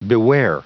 Prononciation du mot beware en anglais (fichier audio)
Prononciation du mot : beware